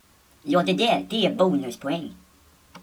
Sköna ljud
Människorna som bor i landet i öster pratar inte som alla andra, vi illustrerar med ett